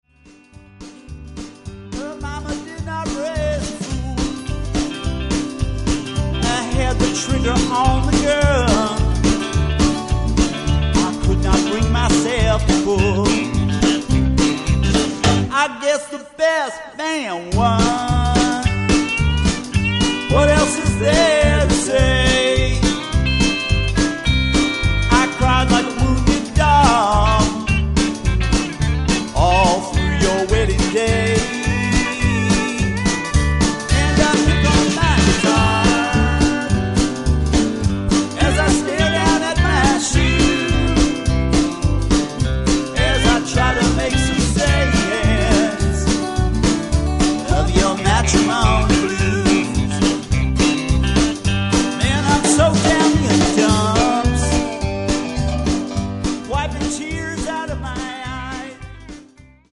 It's in the country vein....very country.
preliminary mix